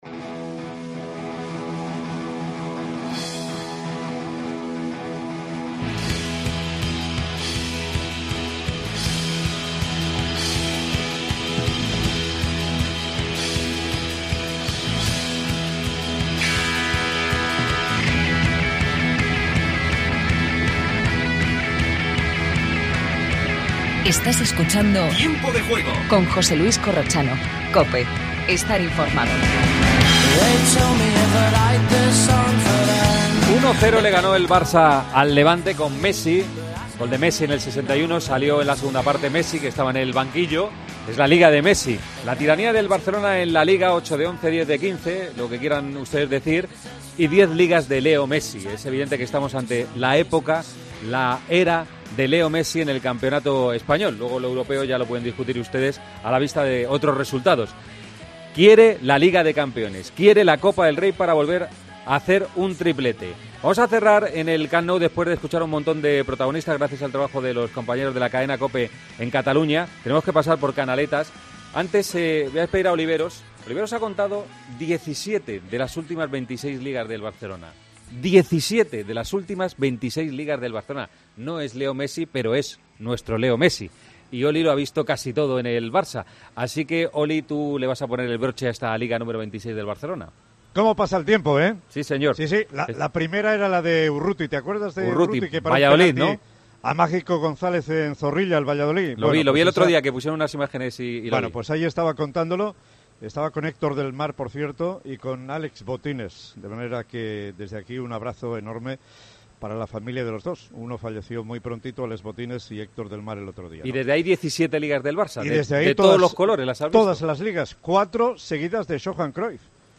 AUDIO: Últimos sonidos de la fiesta del Barça. Repasamos la polémica victoria del Atleti ante el Valladolid. Hablamos con Sergio González.